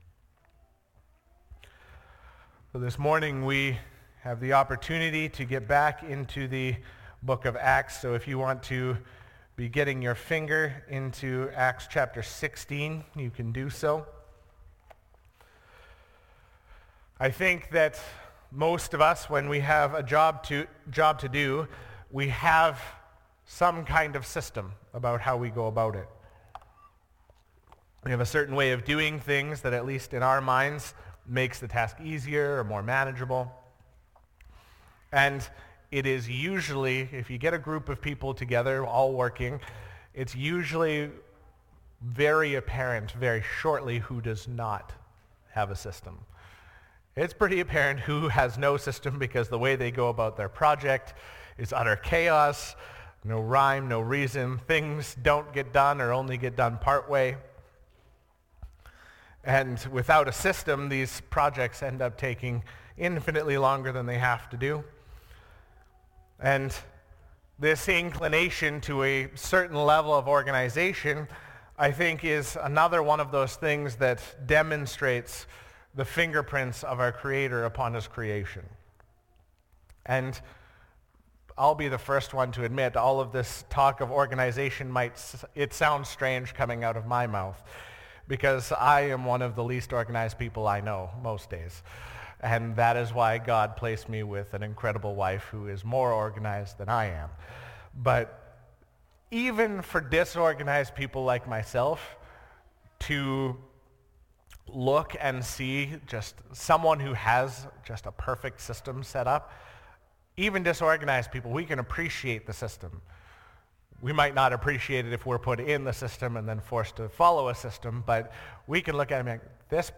Sermons | Elk Point Baptist Church